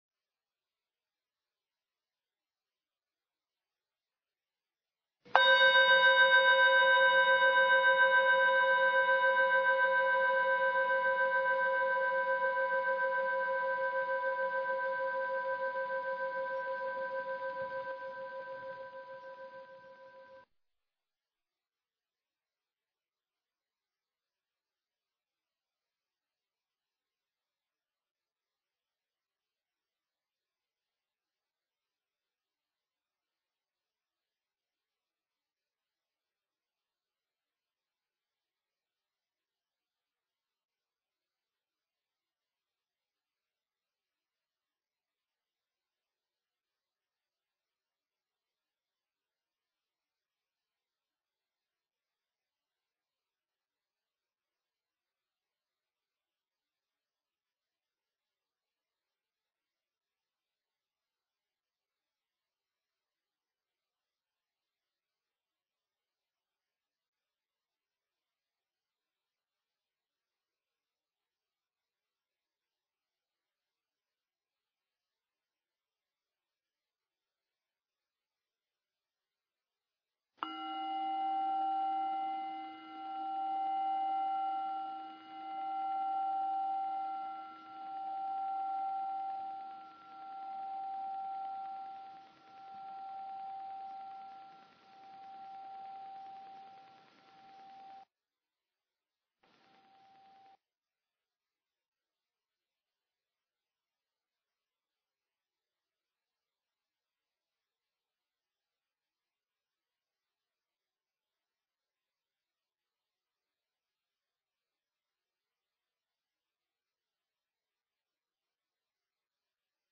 Tiếng chuông đầu tiên thông báo để vào thư giản điều thân và điều tức. 2. Quán Đề Mục: 30 Phút Tiếng chuông thứ nhì thông báo vào công phu Quán Đề Mục. Ba tiếng chuông liên tiếp cuối cùng là Hồi Hướng và Xả Thiền.